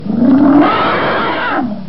دانلود صدای فیل 4 از ساعد نیوز با لینک مستقیم و کیفیت بالا
جلوه های صوتی
برچسب: دانلود آهنگ های افکت صوتی انسان و موجودات زنده دانلود آلبوم انواع صدای فیل از افکت صوتی انسان و موجودات زنده